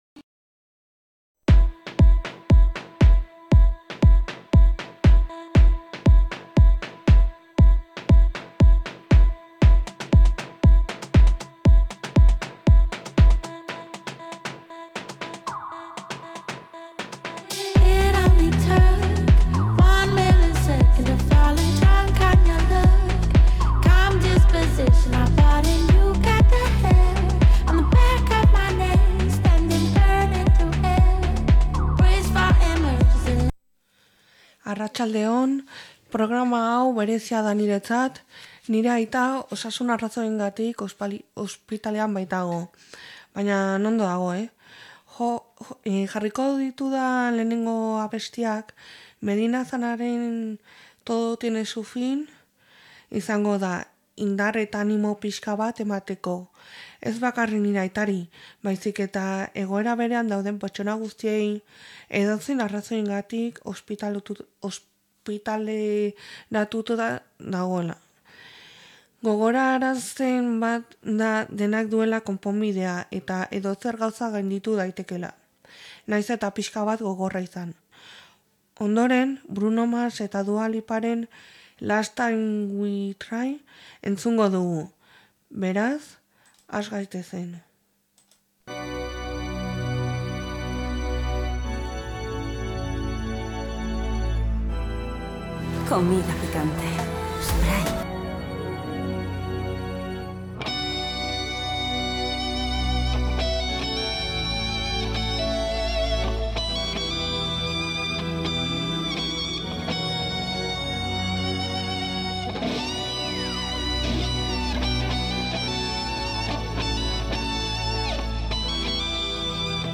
Gaurkoan, rock talde batzuetan zentratuta, musika pixkat entzun degu.